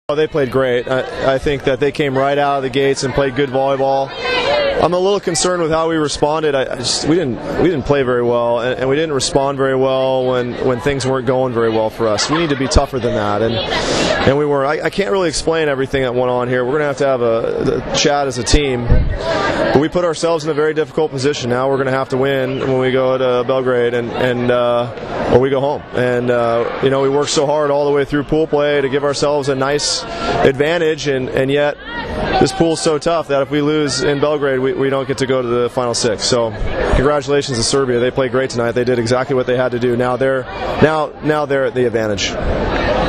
IZJAVA DŽONA SPEROUA